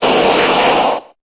Cri de Tortank dans Pokémon Épée et Bouclier.